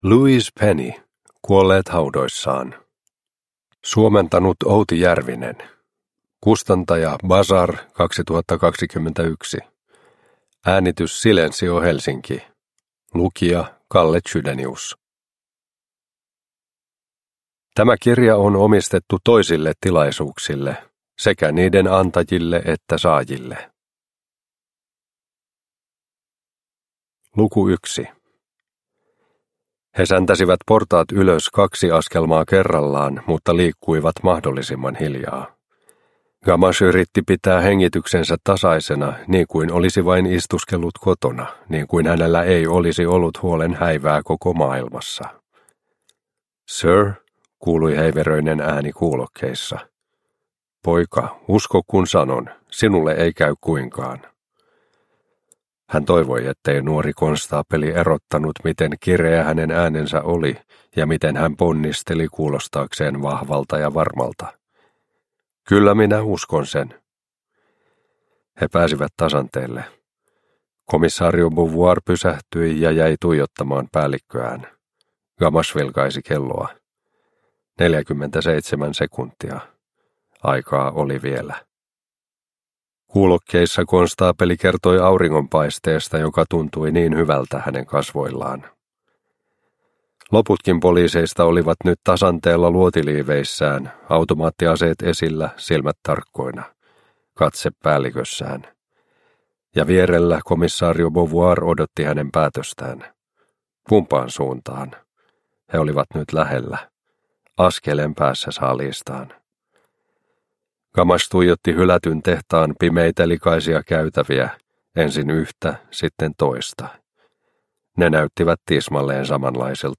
Kuolleet haudoissaan – Ljudbok – Laddas ner